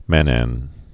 (mănăn, -ən)